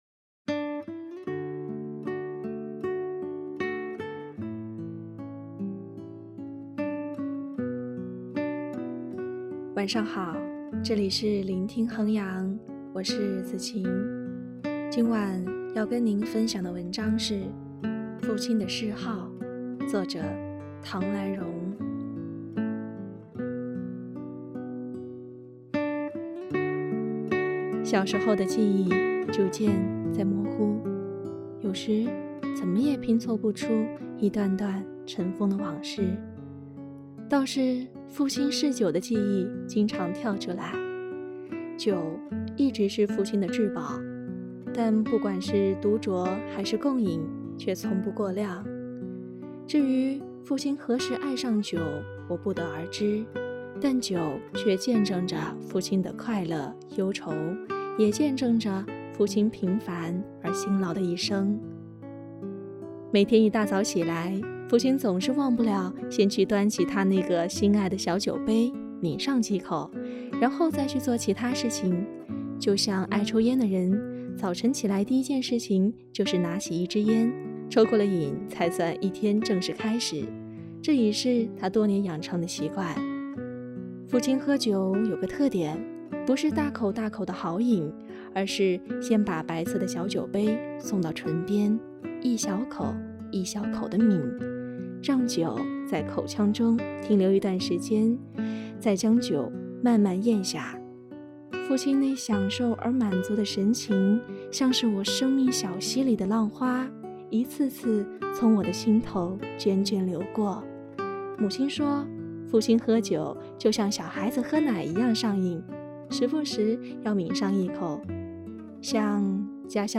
■朗读者